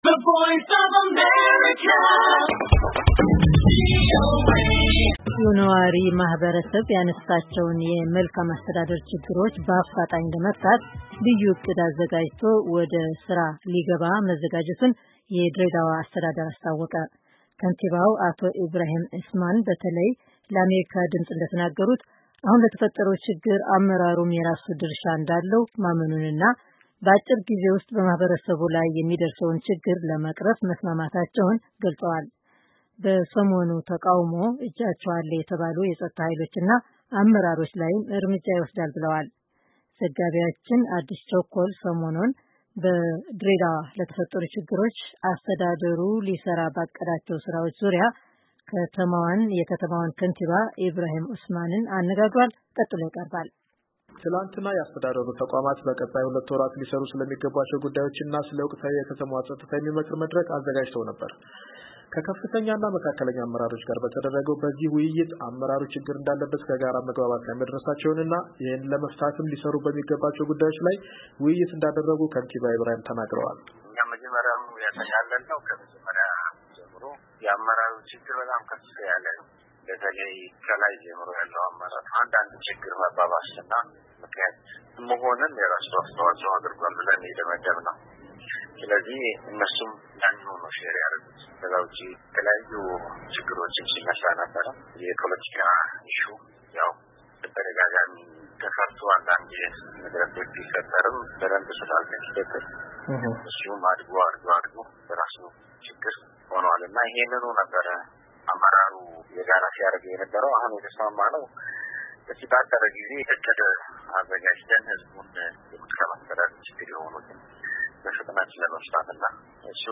ከድሬዳዋ ከንቲባ ጋር የተደረገ ቃለ ምልልስ